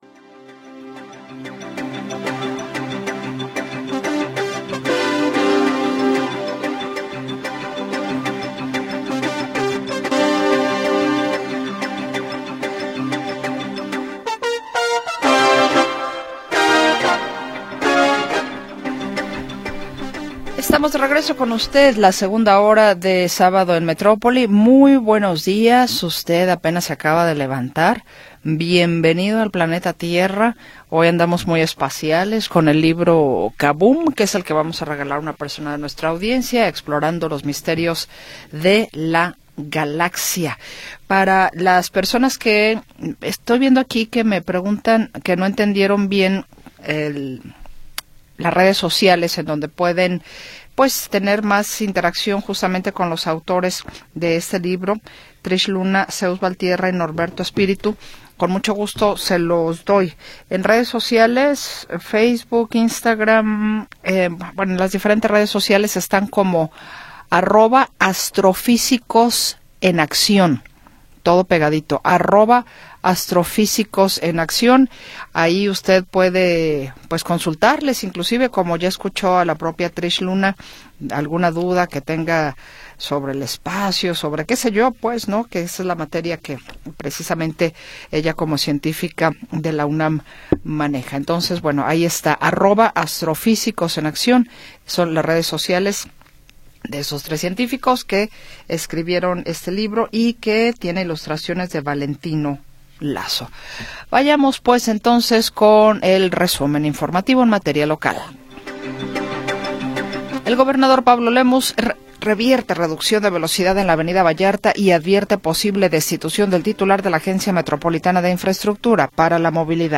Segunda hora del programa transmitido el 10 de Enero de 2026.